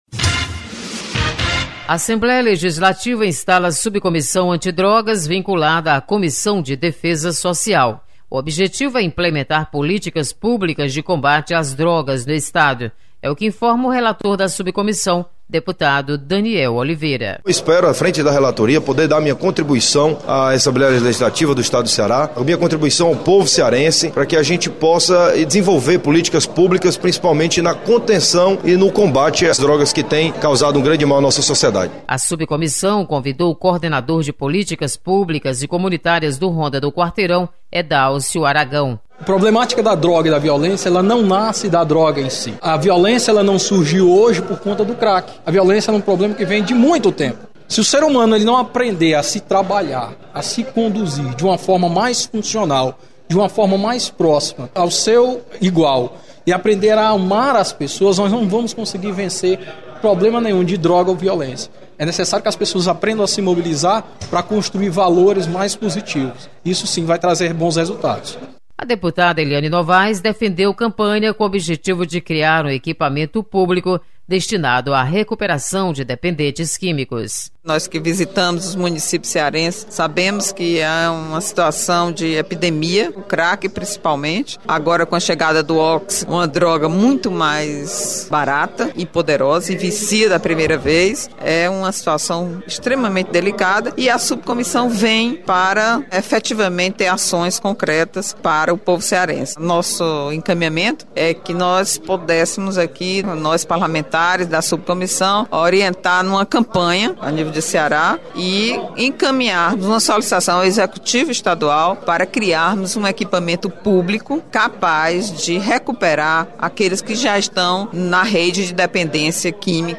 Programa diário com reportagens, entrevistas e prestação de serviços